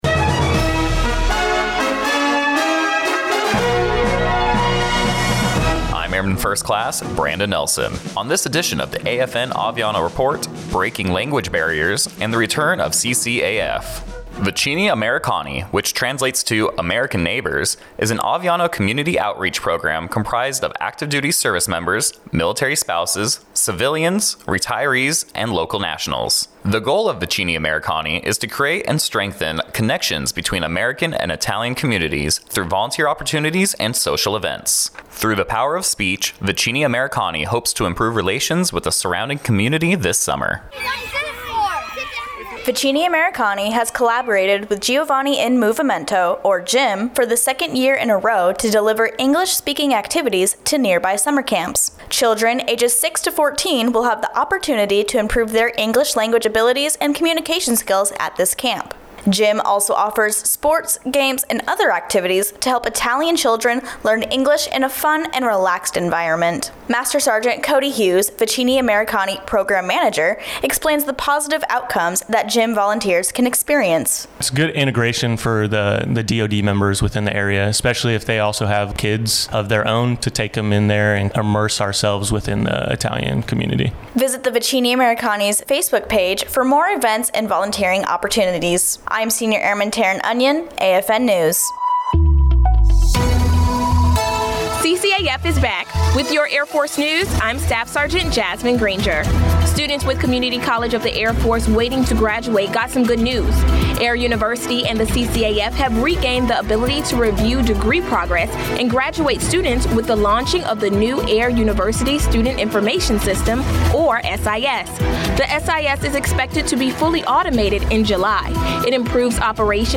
American Forces Network (AFN) Aviano radio news reports on Vicini Americani partnering with Giovani in Movimento to bring English speaking service members into Italian summer camps to help children learn English.